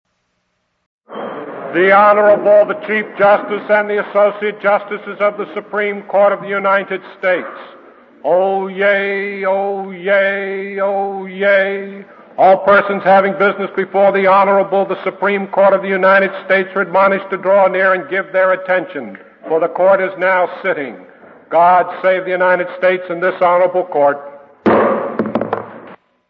It is a solemn moment, full of drama and importance, when the Marshal of the Supreme Court gavels the Supreme Court into session and
solemnly intones:
oyezoyezoyez.mp3